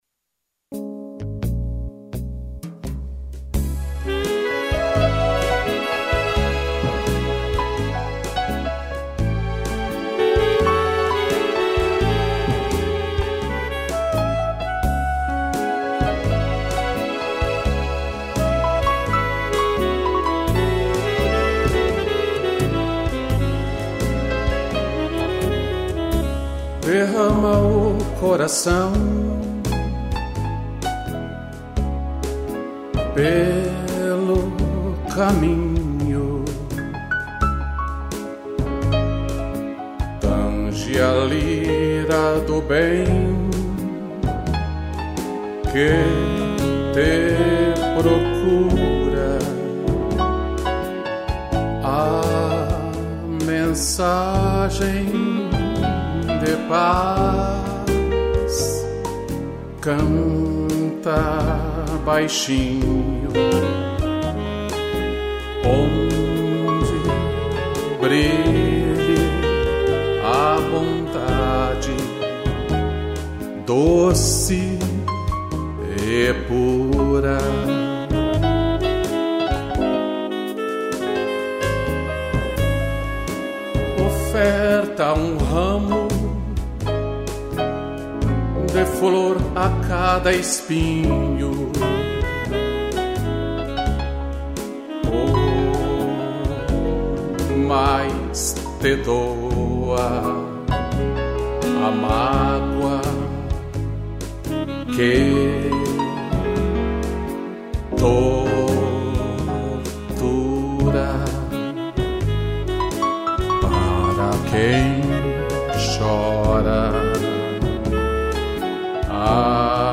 voz
piano e sax